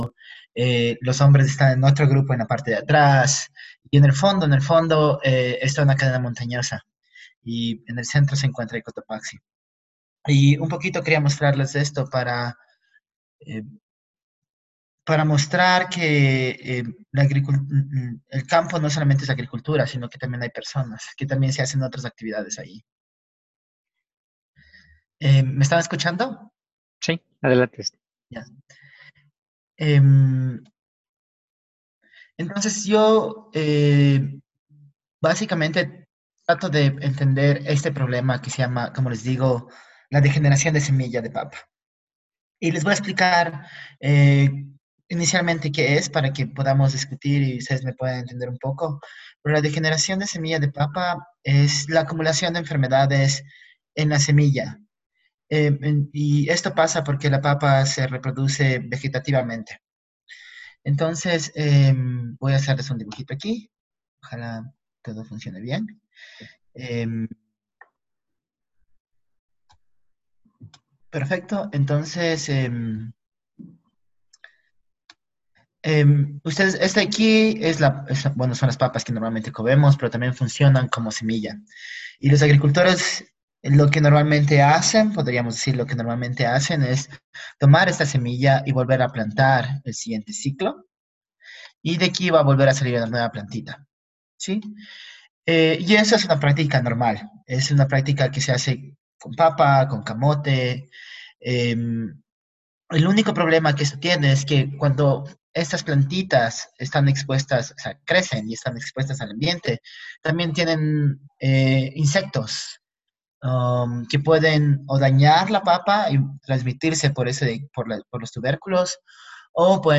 El webinario
El presentará su proceso y tendremos la oportunidad de hacer preguntas y compartir experiencias.